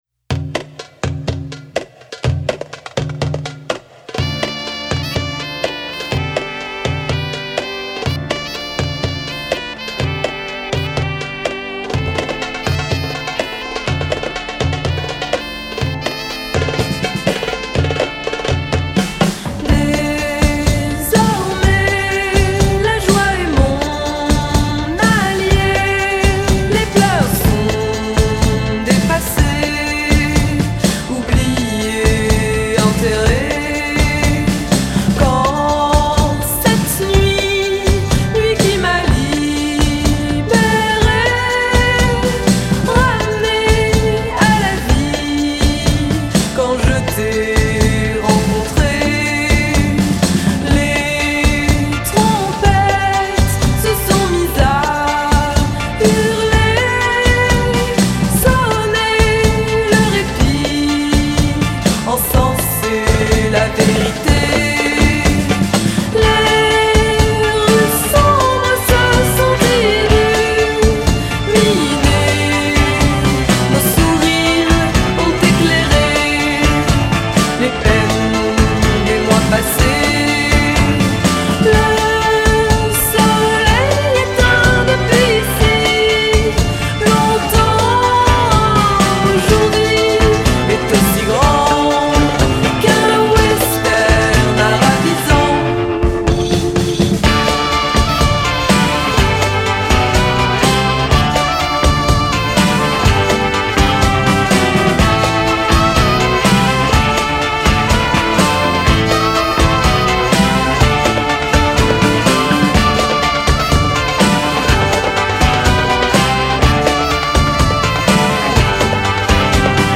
autrice, compositrice, interprète, co-productrice
24 - 55 ans - Mezzo-soprano